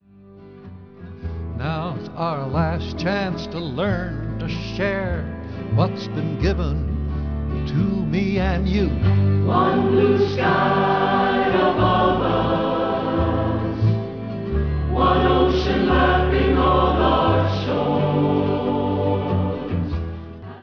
12-string guitar
6-string guitar
bass
chorus